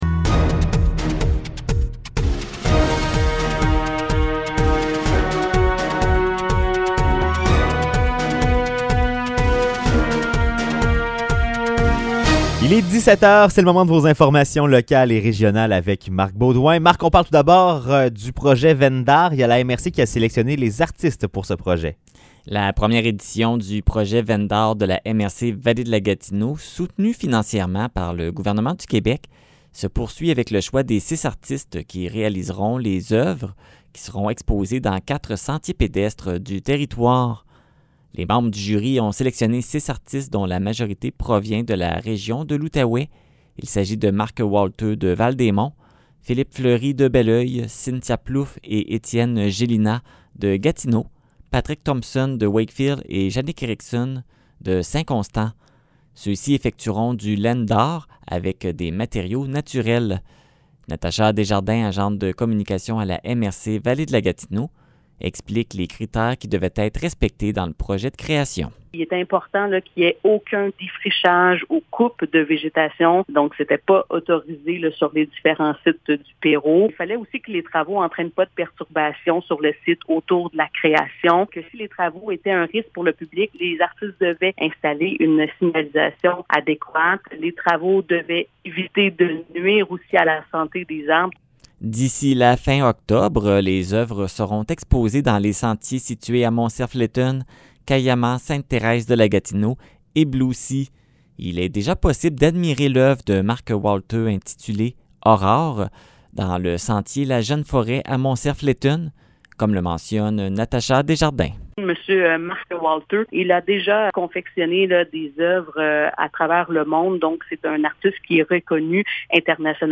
Nouvelles locales - 10 août 2021 - 17 h